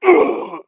client / bin / pack / Sound / sound / monster / bou / dead_1_1.wav
dead_1_1.wav